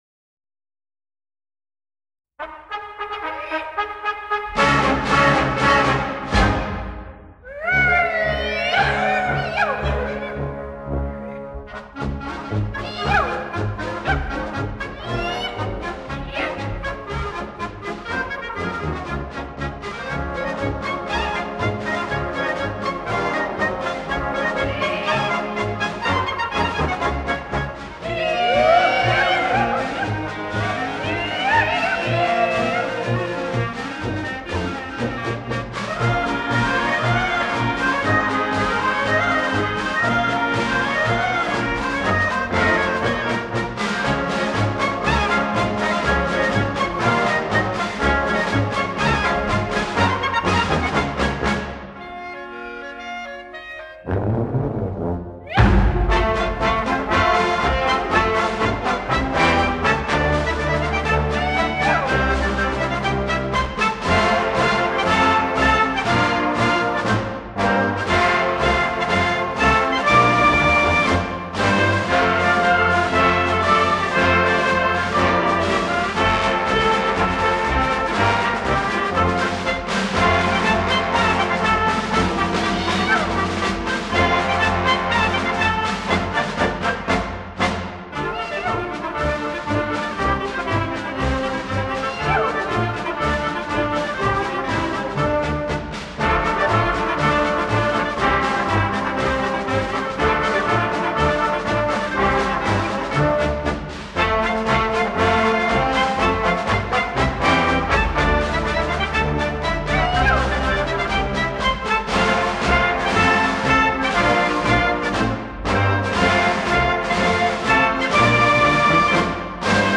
0071-风笛名曲卷起裤子.mp3